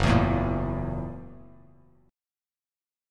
F#7#9 chord